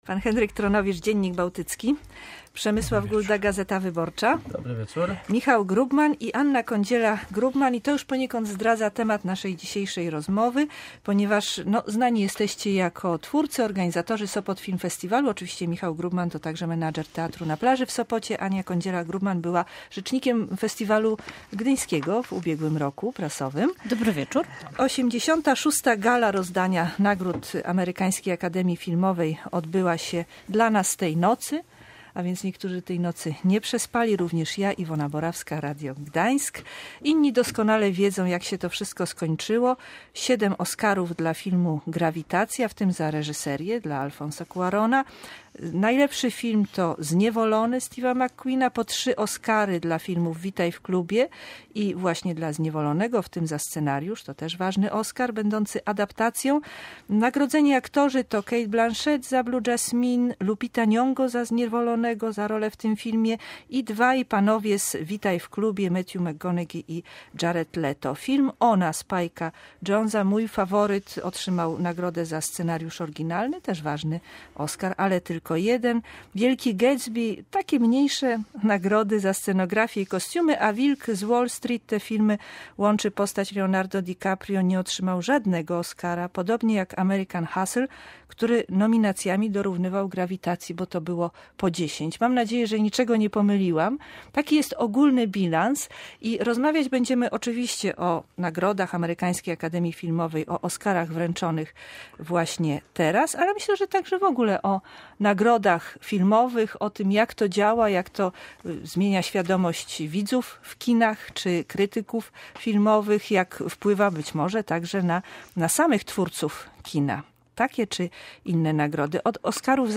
Debata o Oskarach